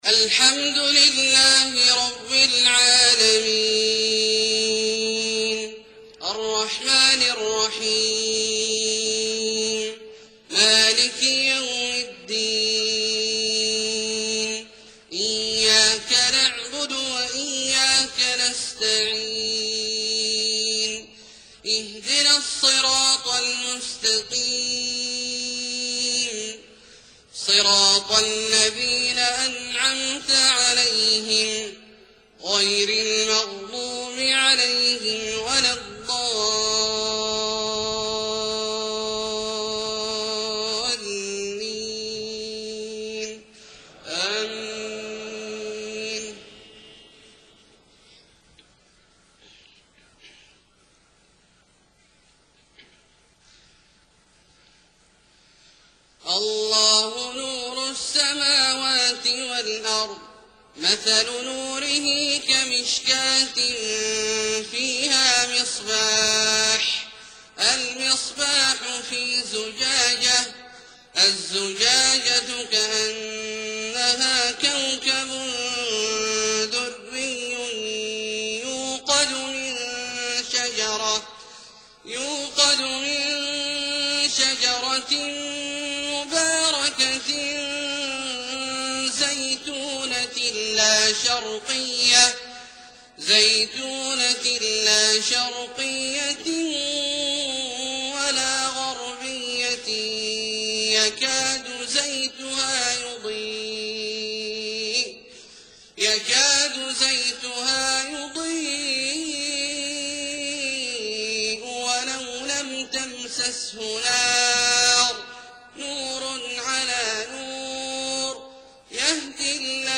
صلاة العشاء 3-4-1430 من سورة النور {35-52} > ١٤٣٠ هـ > الفروض - تلاوات عبدالله الجهني